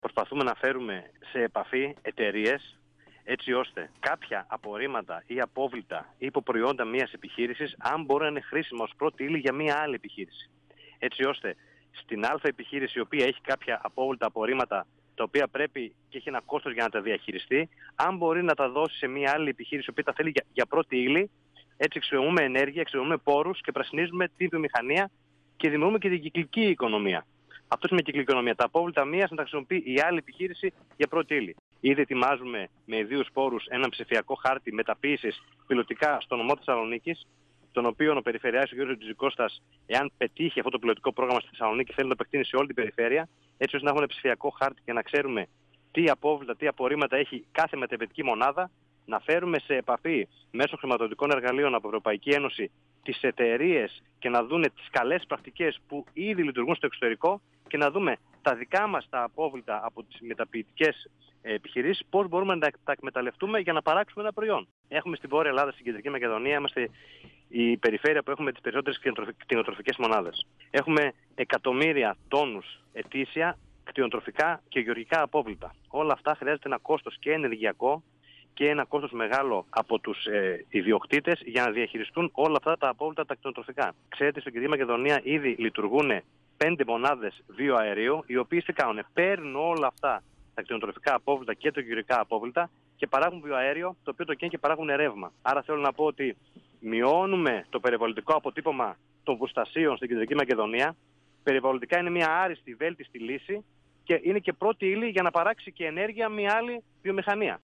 Ο αντιπεριφερειάρχης Ανάπτυξης και Περιβάλλοντος Κεντρικής Μακεδονίας, Κώστας Γιουτίκας, στον 102FM του Ρ.Σ.Μ. της ΕΡΤ3
Συνέντευξη